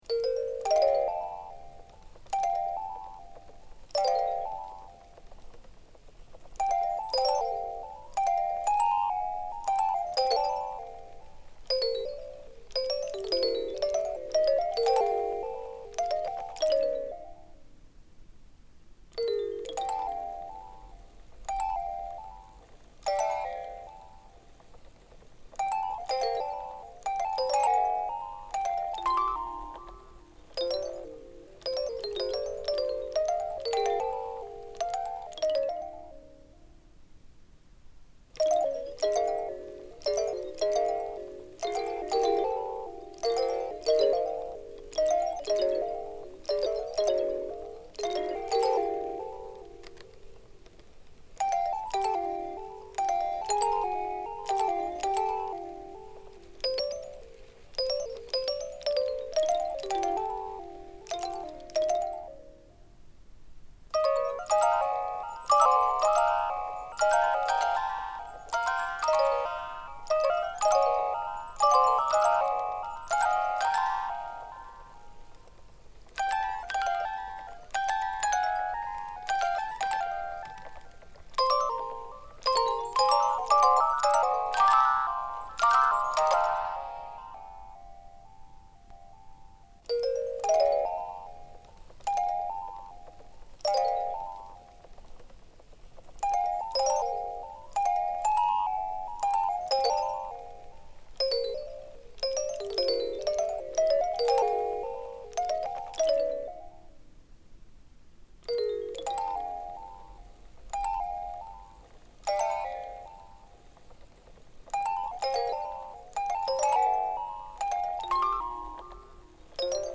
ビデオとパフォーマンスのサウンドトラックとしてつくったものです。
オルゴール